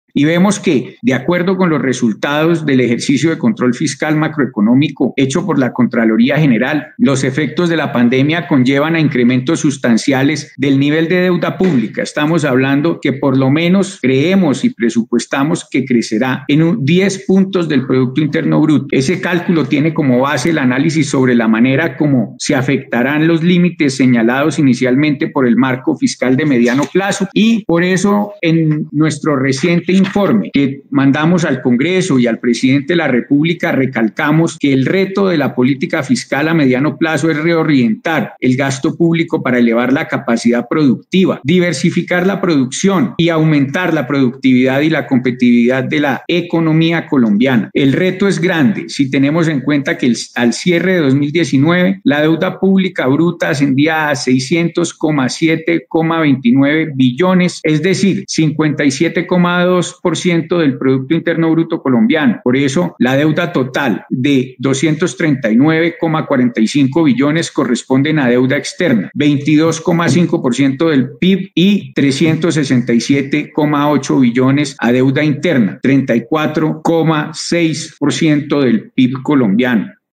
En un Foro sobre ¿Cuánto le está costando la pandemia a la Nación?, convocado por la CGR y Foros Semana, el Contralor habló de los enormes costos económicos y sociales que ha significado esta emergencia.
AUDIO: Carlos Felipe Córdoba, Contralor General de la República